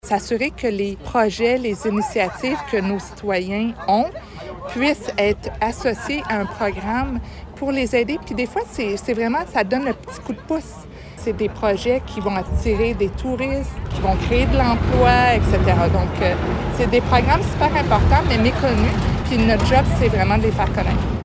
La ministre parle de mesures budgétaires qui ont été adoptées, dans ce contexte, afin d’aider les agriculteurs. La ministre de l’Agriculture et de l’Agroalimentaire du Canada, Marie-Claude Bibeau, commente :